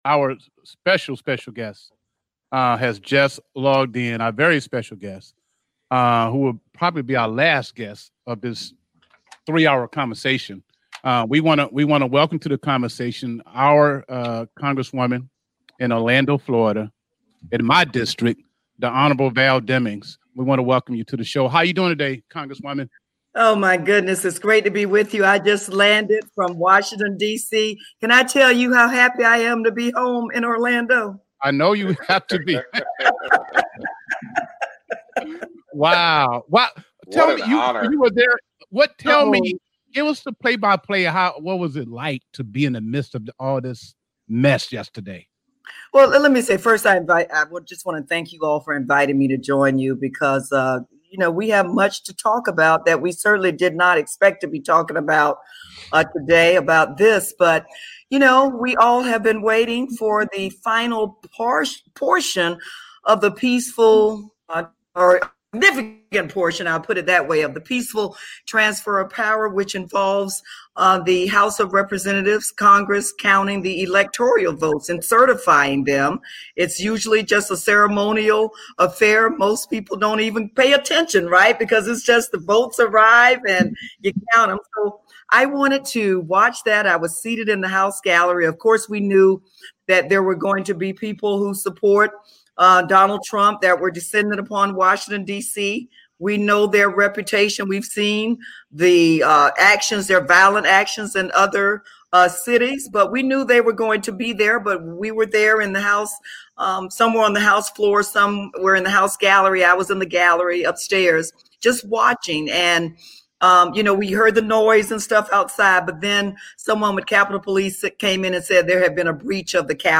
Interview with Val Demings regarding Capitol Riot
Interview with Congress woman Val Demings and her thoughts on the Capitol riot